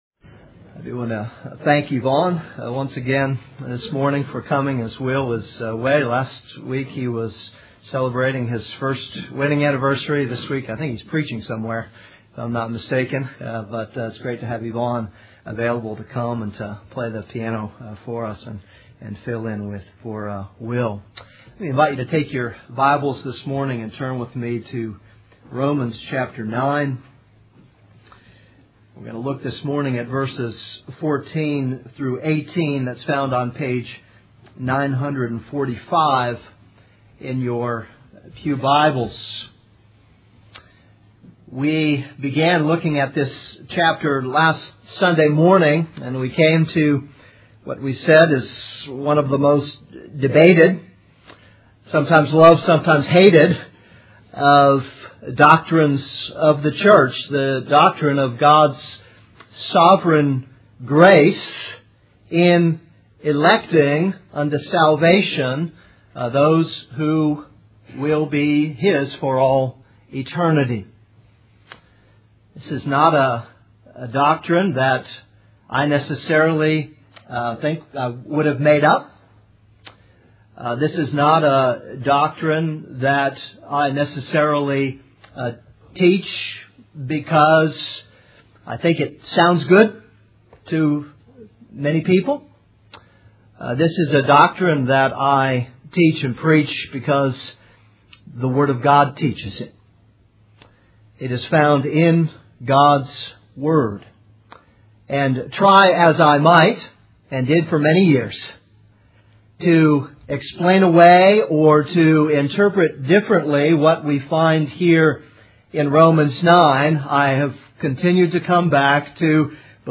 This is a sermon on Romans 9:14-18.